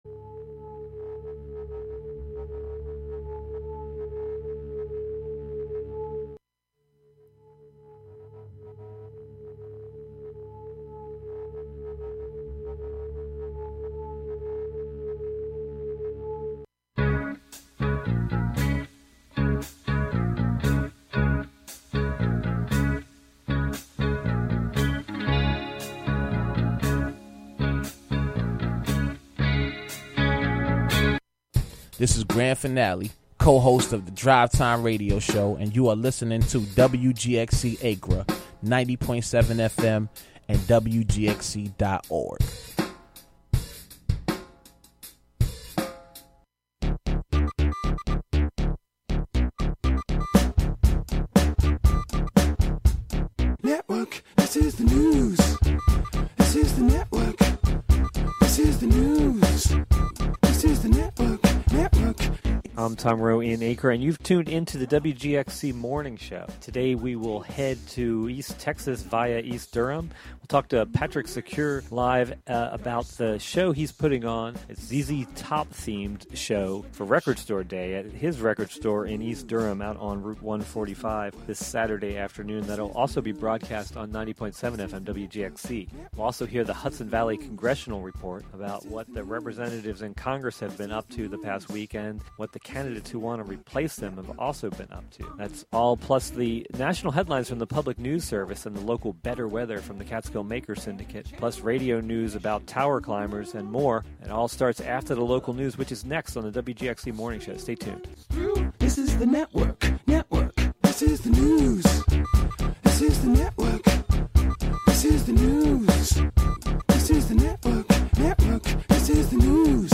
Contributions from many WGXC programmers.
WGXC's Hudson Valley Congressional Report tracks the votes, speeches, positions, fundraising, and appearances of the representatives in Congress from the Hudson Valley, and the candidates who want to replace them in November. The "WGXC Morning Show" is a radio magazine show featuring local news, interviews with community leaders and personalities, reports on cultural issues, a rundown of public meetings and local and regional events, with weather updates, and more about and for the community, made mostly through volunteers in the community through WGXC.